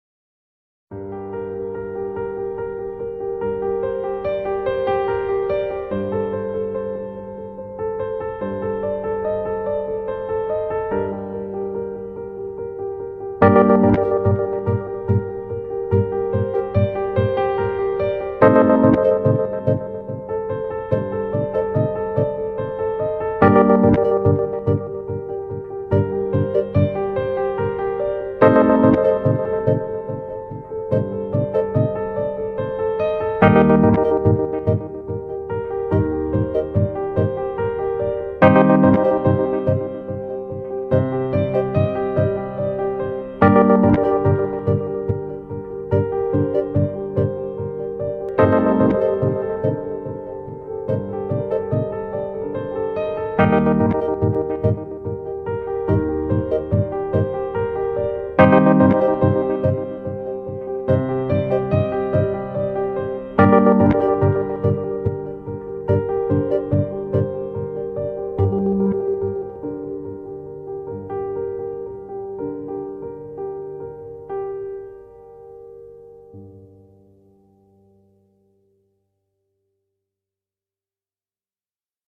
tema dizi müziği, duygusal hüzünlü üzgün fon müziği.